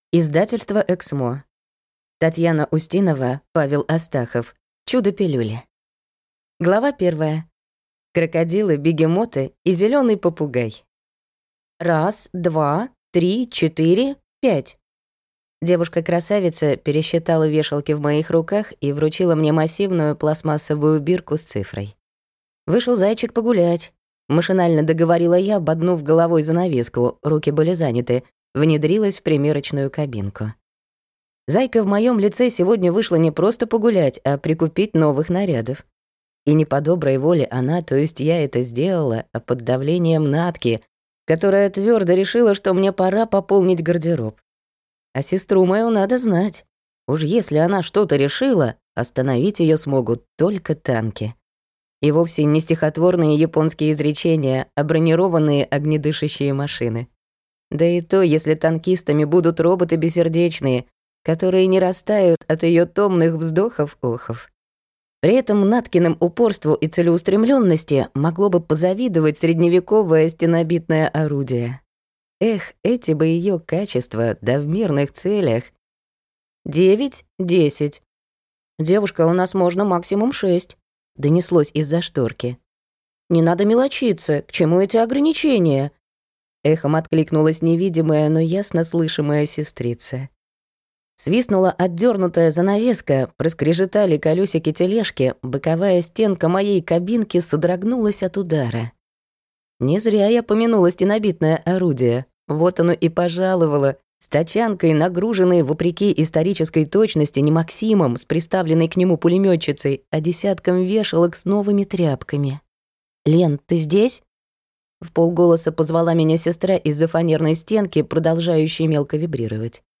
Аудиокнига Чудо-пилюли | Библиотека аудиокниг